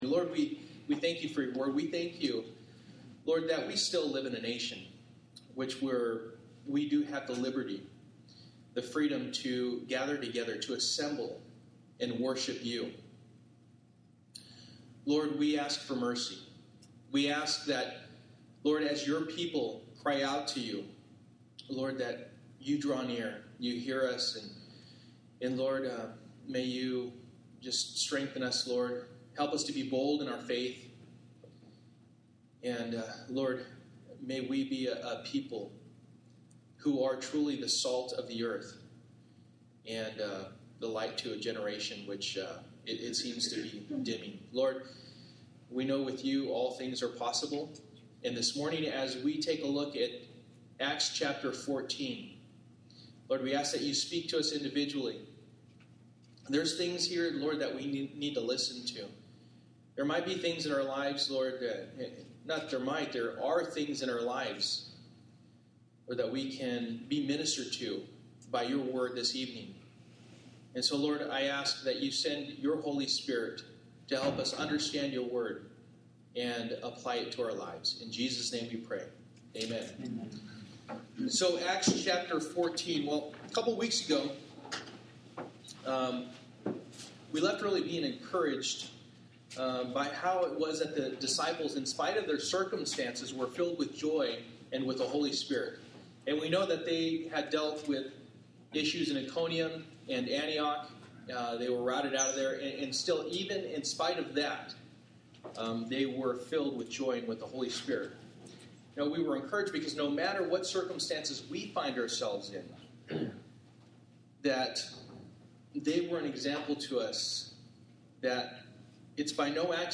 Acts Passage: Acts 14:8-28 Service: Wednesday Night %todo_render% « Authentic